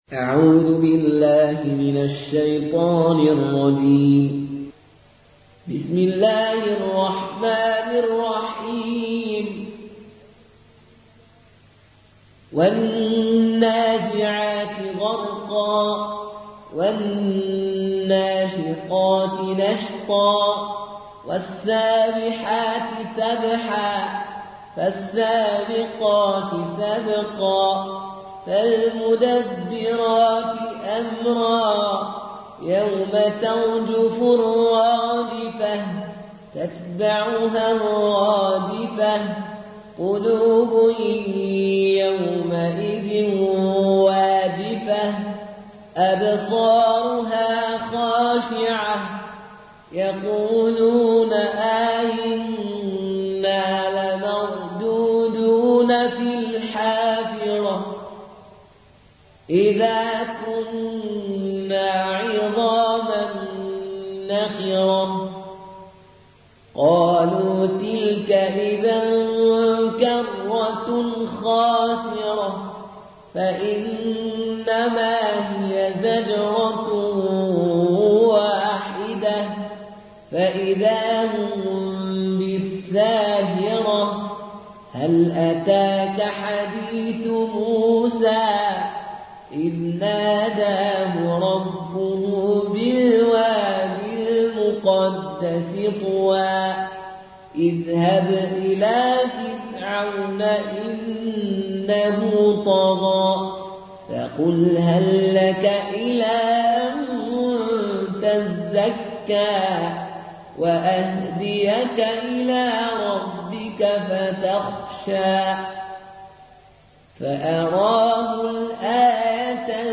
Qaloon থেকে Nafi, ডাউনলোড করুন এবং কুরআন শুনুন mp3 সম্পূর্ণ সরাসরি লিঙ্ক